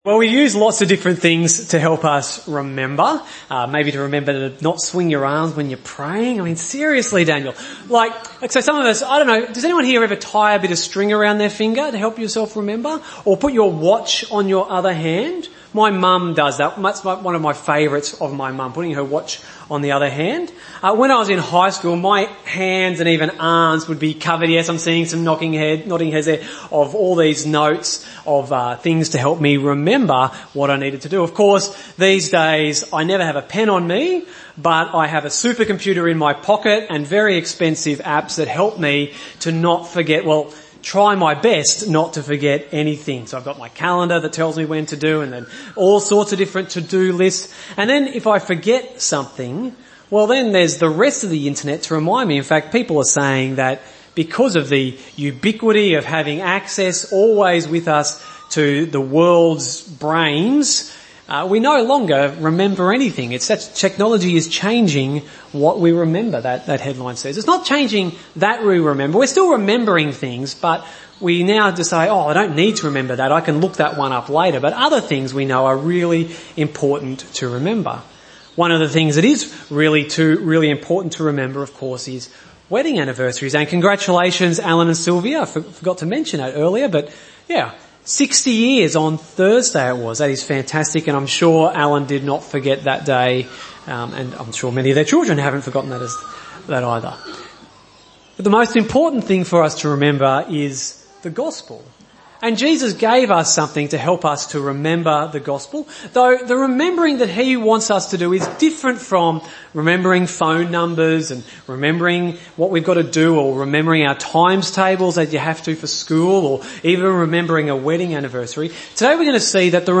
Gympie Presbyterian Church